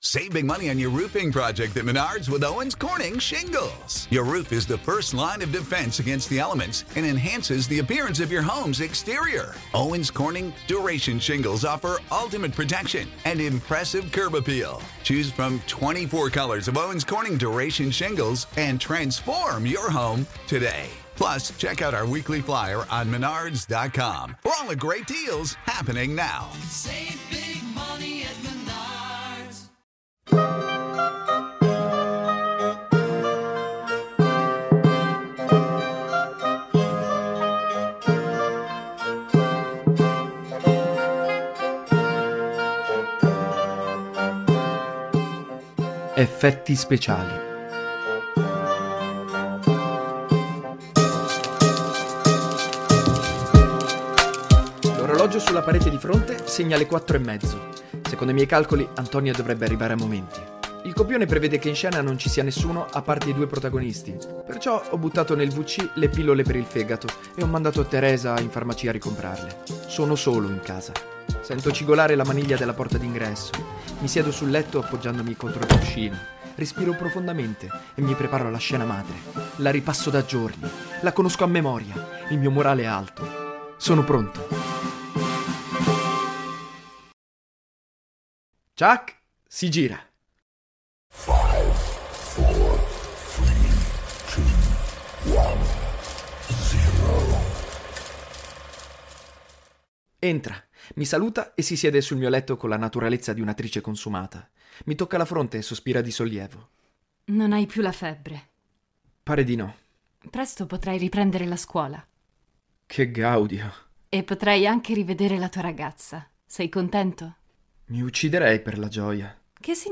Un altro episodio cruciale del romanzo-podcast.